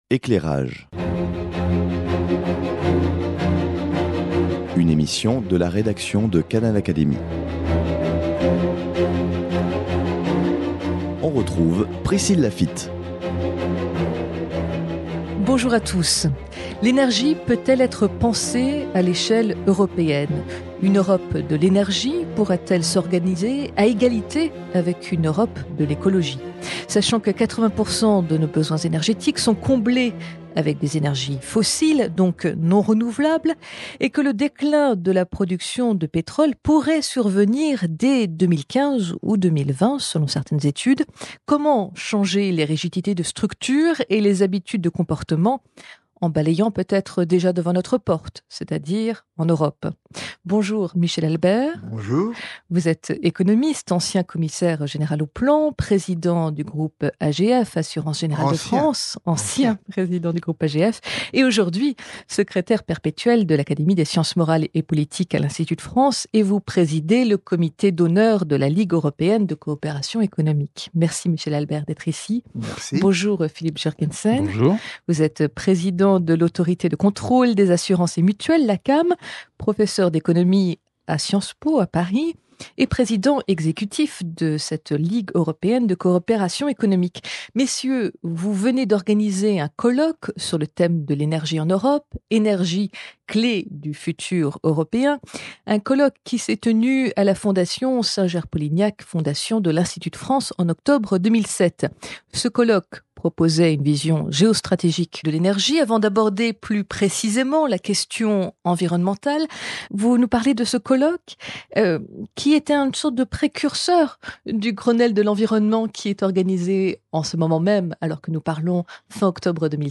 C’était le thème d’un colloque organisé à la Fondation Singer-Polignac en octobre 2007.